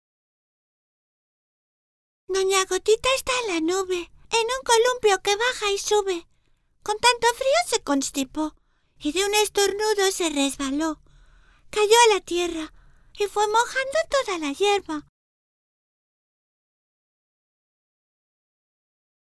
Sprechprobe: Sonstiges (Muttersprache):
Versatile, heart-warming and professional.